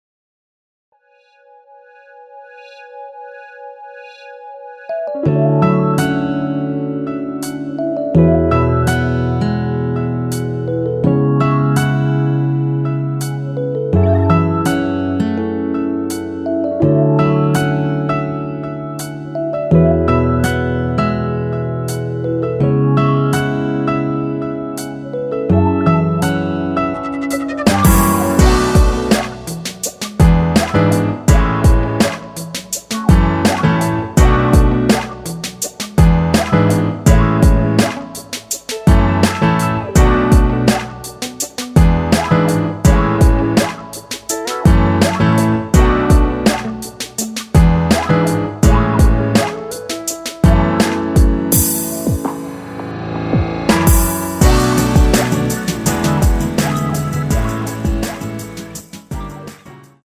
음정은 반음정씩 변하게 되며 노래방도 마찬가지로 반음정씩 변하게 됩니다.
앞부분30초, 뒷부분30초씩 편집해서 올려 드리고 있습니다.
중간에 음이 끈어지고 다시 나오는 이유는
위처럼 미리듣기를 만들어서 그렇습니다.